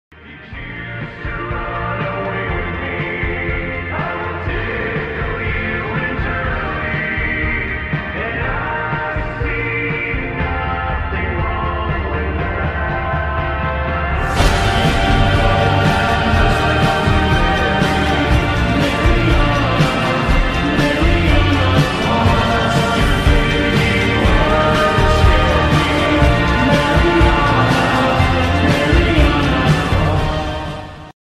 State Funeral of John F. Kennedy (23 November 1963 - 25 November 1963)